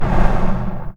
MONSTER_Breath_04_Fast_mono.wav